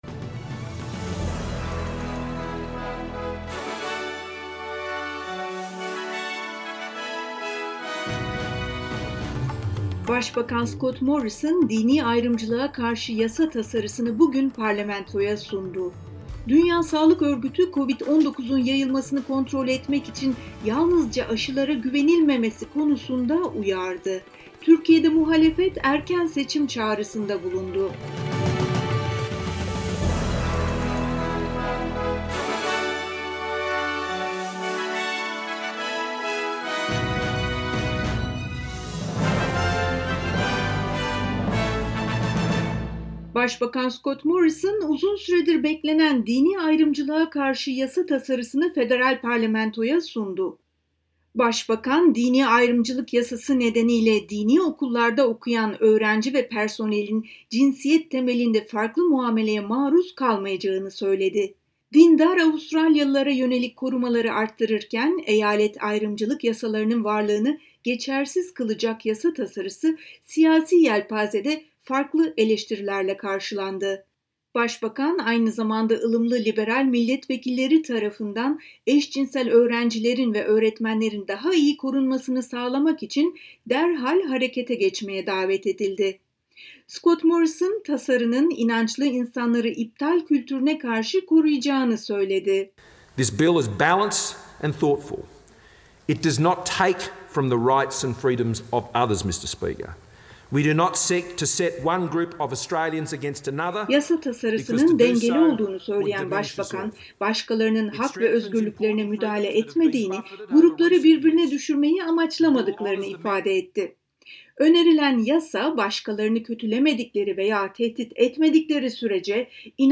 SBS Türkçe Haberler 25 Kasım